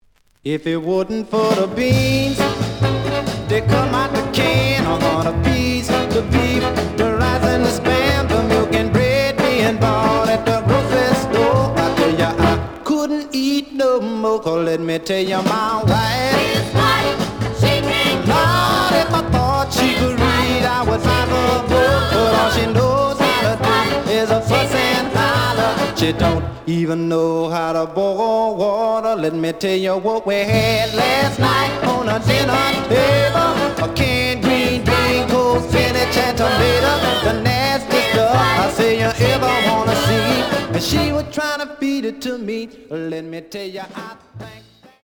The audio sample is recorded from the actual item.
●Genre: Rhythm And Blues / Rock 'n' Roll
Slight affect sound.